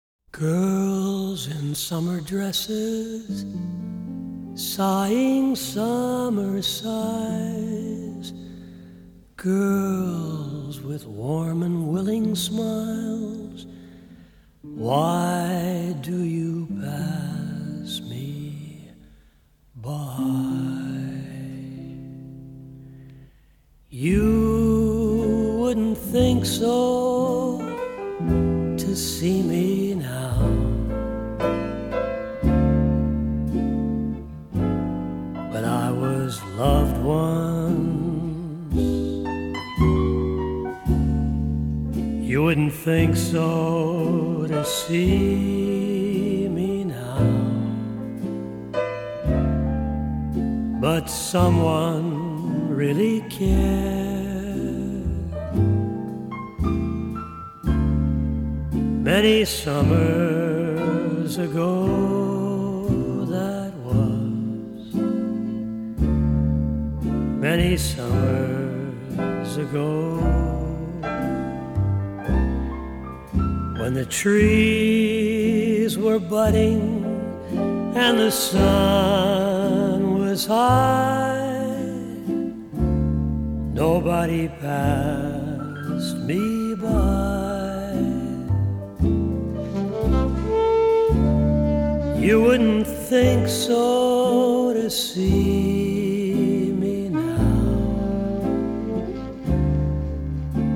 ★柔和而富磁性的歌聲，輕緩悠揚的爵士風情，為您在夜間點上一盞綻放溫暖光芒的燈。
低沉富磁性的嗓音更增添了歲月的風采，傳統爵士的鋼琴、吉他、薩克斯風外，更以大提琴、笛子等增添浪漫悠揚的感性